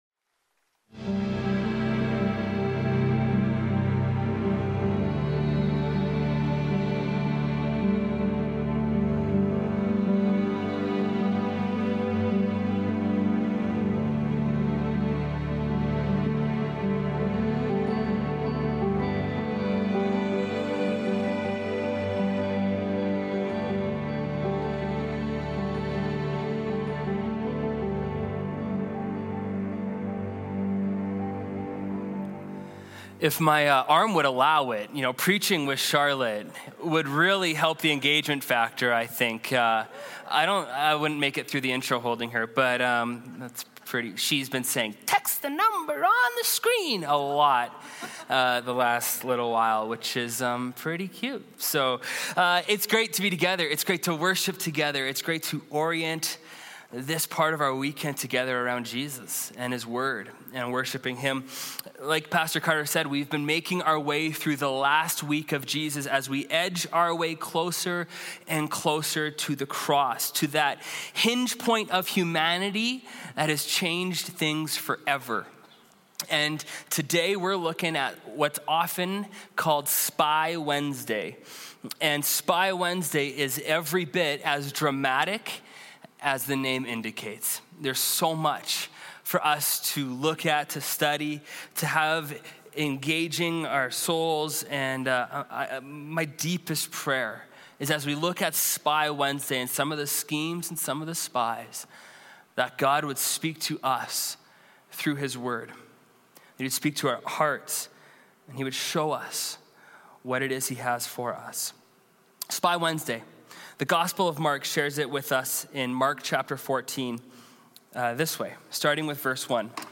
English Teaching MP3 This Weekend's Scriptures...